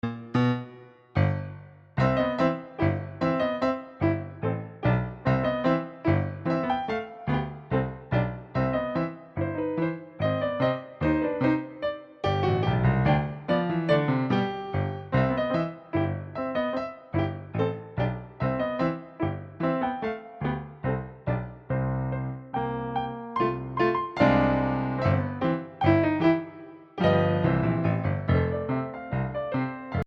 Voicing: 1 Piano 6 Hands